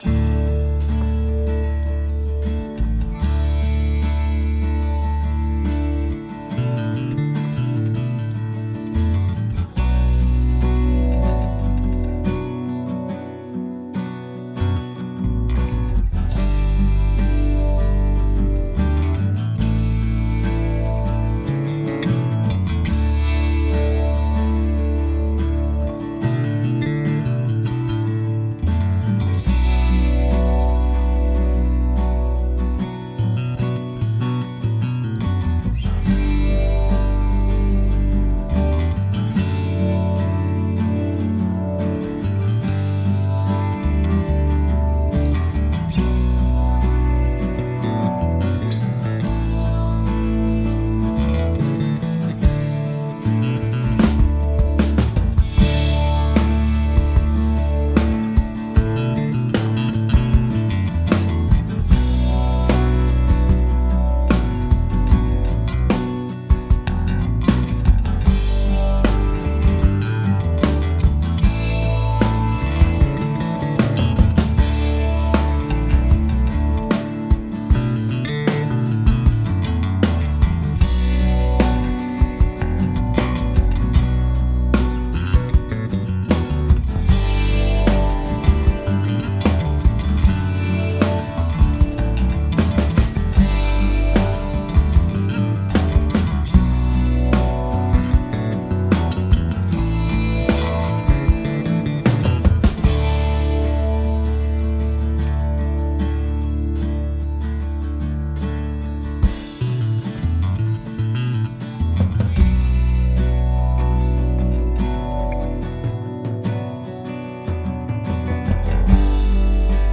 All sound clips have been self produced there or with one of my mobile recording rigs. Most clips are actual songs I've played and recorded featuring the Highlighted Instrument / Amp etc. in a real world mix, not a straight up raw sounding demo.
Fender American Standard Jazz Bass
All Creatures Of Our God And King - No Vocals.mp3